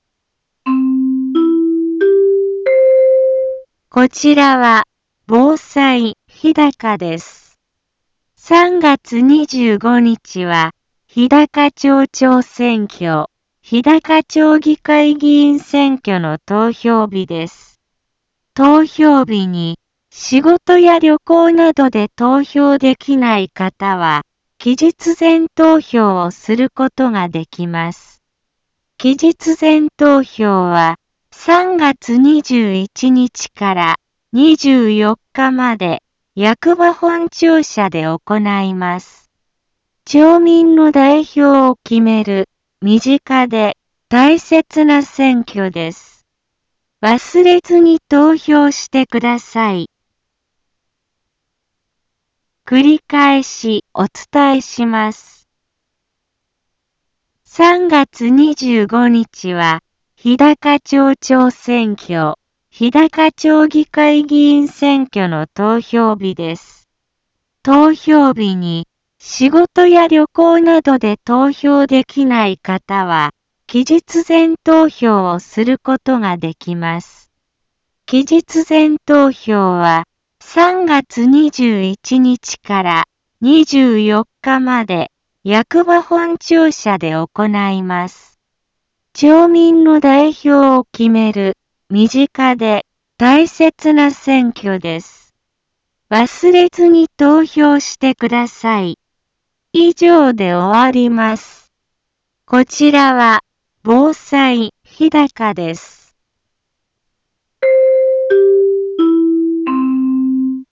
Back Home 一般放送情報 音声放送 再生 一般放送情報 登録日時：2018-03-16 10:05:22 タイトル：日高町長選挙、日高町議会議員選挙のお知らせ インフォメーション：３月２５日は日高町長選挙、日高町議会議員選挙の投票日です。